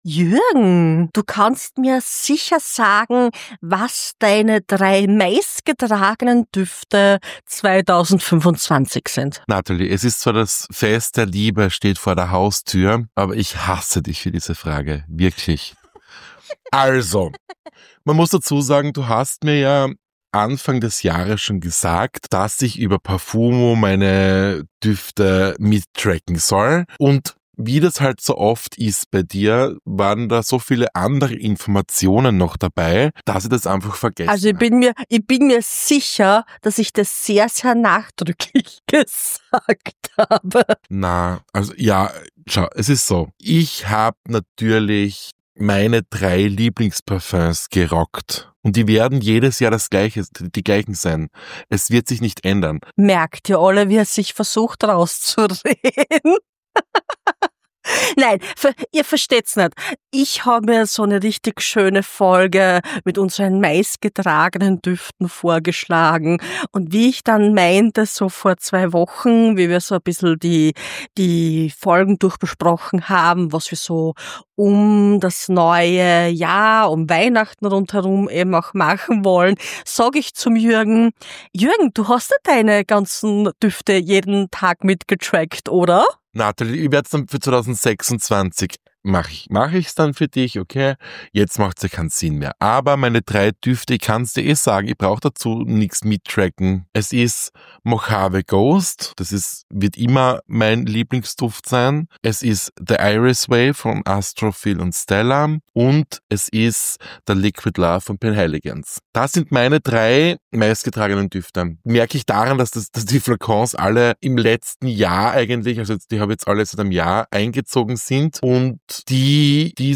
Sagen wir so: Es wird festlich, es wird cozy und es bleibt nicht ganz ohne Versprecher In dieser Folge von Riechst du das?